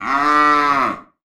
めばえ４月号 ウシのなきごえ